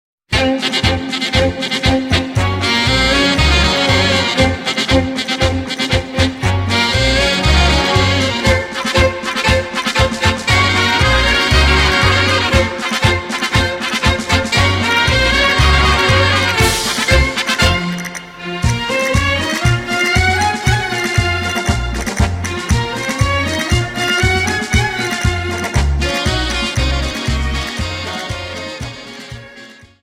Dance: Paso Doble Song